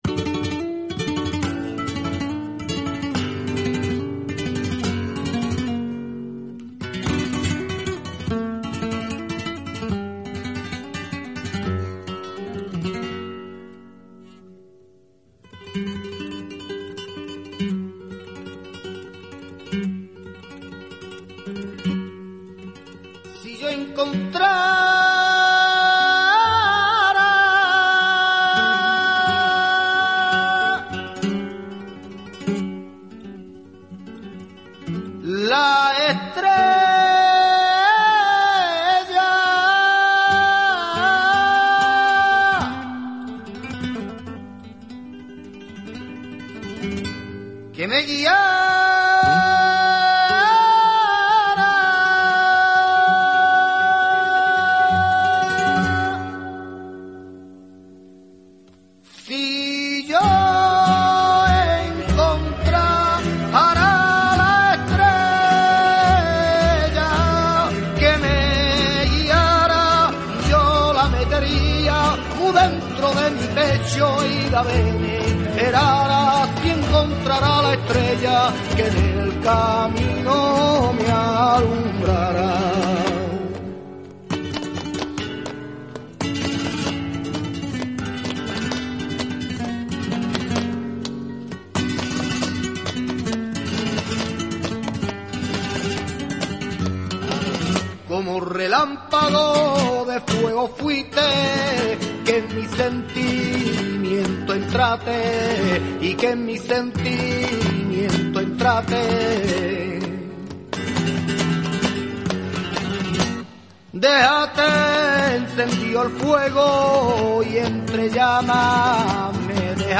Un reportaje de los alumnos de la Universidad Rey Juan Carlos ha inaugurado la programación